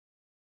sounds / monsters / fracture / hit_3.ogg
hit_3.ogg